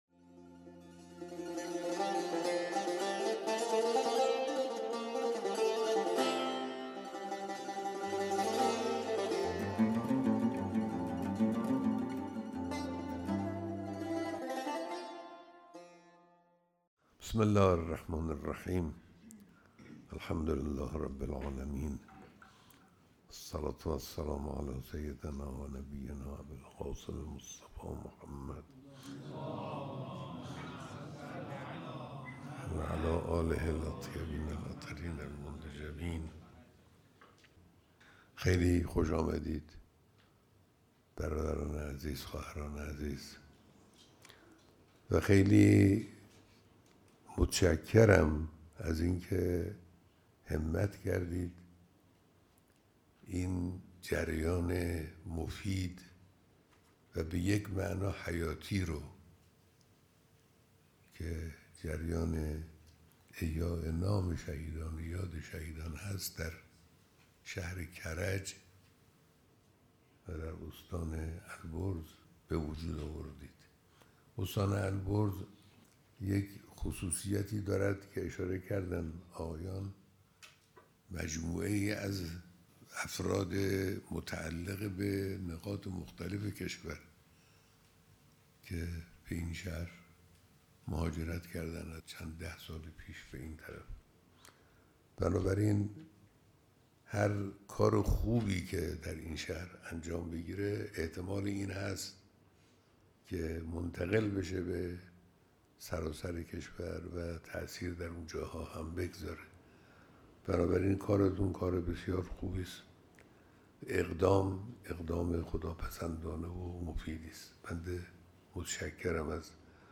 بیانات در دیدار دست‌اندرکاران کنگره بزرگداشت شهدای استان البرز